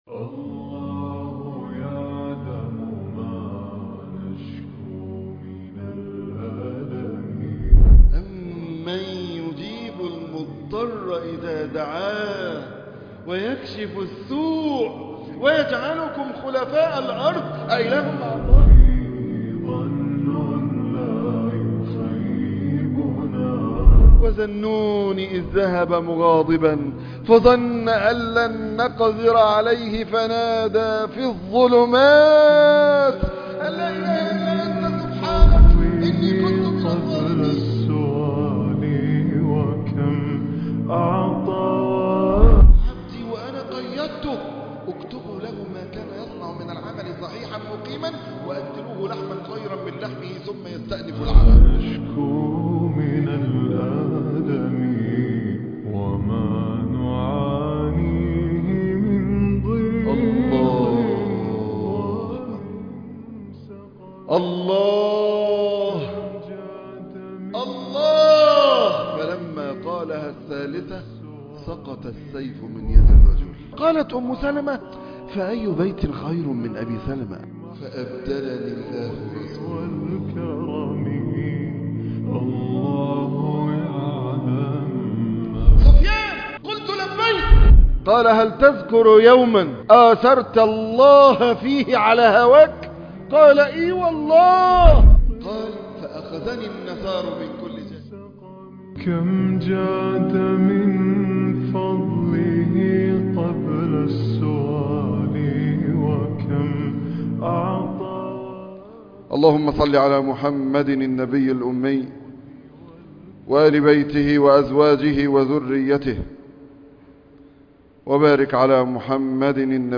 كيف أحزن؟! خطبة تزيل همك